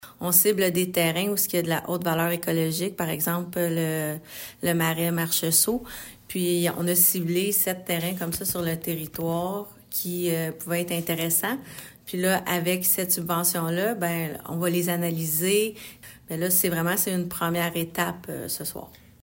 La mairesse de Bromont, Tatiana Contreras :